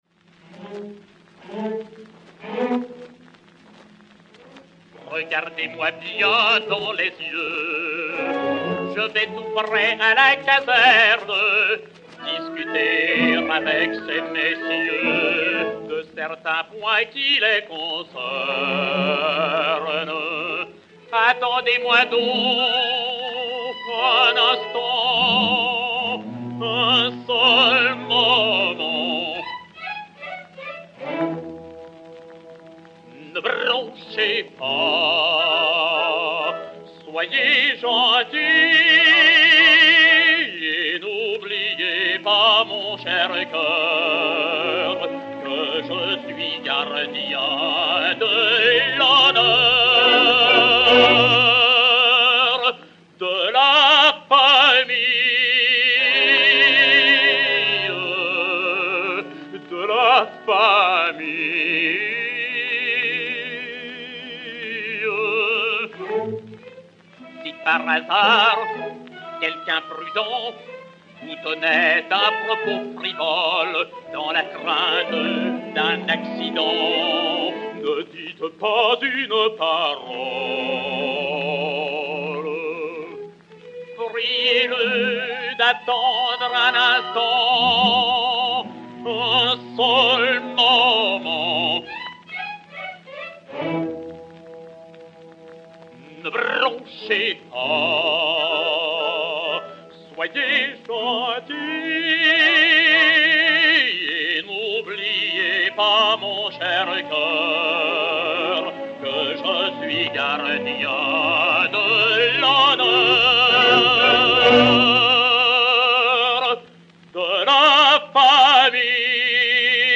baryton français